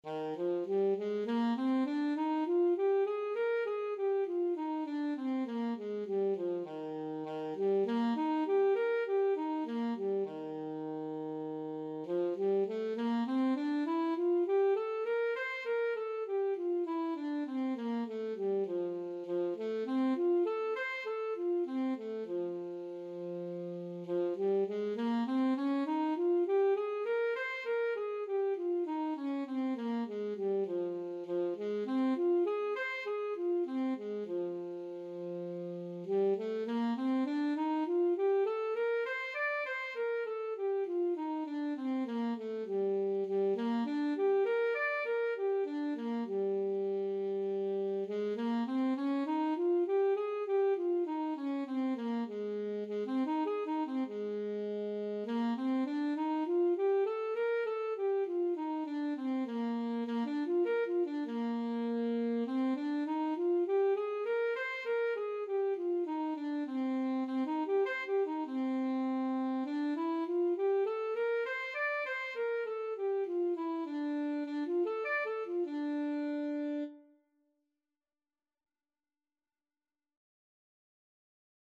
Classical Saxophone scales and arpeggios - Grade 2 Alto Saxophone version
Saxophone scales and arpeggios - Grade 2
Eb major (Sounding Pitch) C major (Alto Saxophone in Eb) (View more Eb major Music for Saxophone )
4/4 (View more 4/4 Music)
Eb4-D6
saxophone_scales_grade2_ASAX.mp3